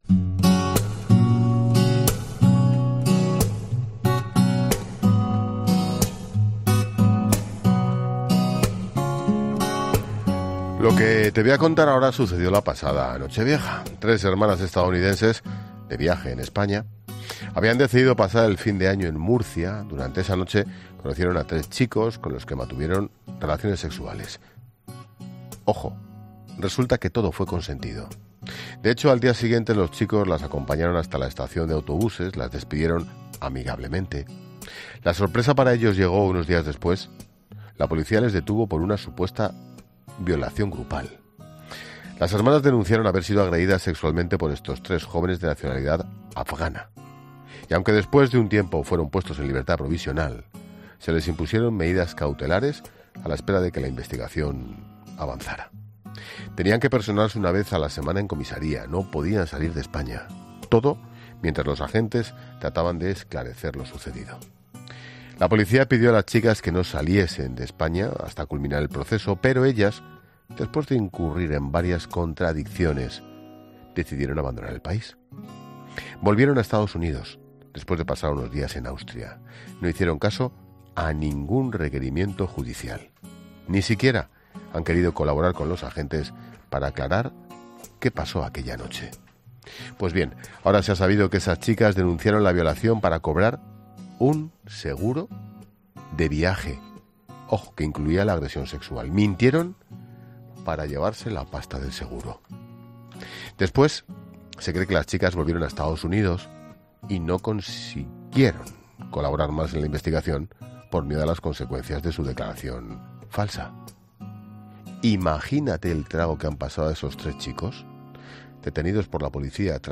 El presentador de 'La Linterna', Ángel Expósito ha contado lo que sucedió la pasada Nochevieja.